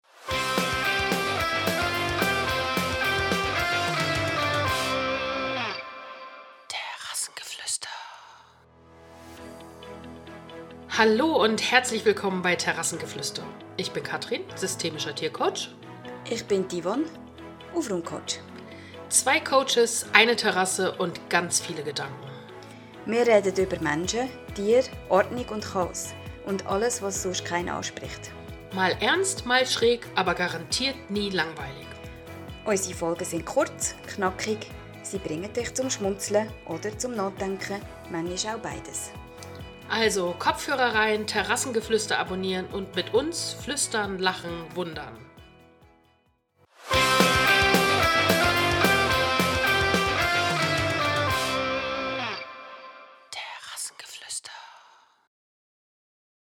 Zwei Frauen, zwei Blickwinkel, ein Ort zum Ankommen.
Im Podcast Terrassengeflüster treffen sich eine systemische Tiercoachin und eine Aufräumcoachin zum Gespräch über das Leben – innen wie außen.